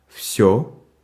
Ääntäminen
Vaihtoehtoiset kirjoitusmuodot (rikkinäinen englanti) orl Synonyymit completely everything Ääntäminen US : IPA : /ˈɔl/ UK : IPA : /ˈɔːl/ cot-caught: IPA : [ɑɫ] Northern Cities Vowel Shift: IPA : [ɑɫ] Tuntematon aksentti: IPA : /ˈɑl/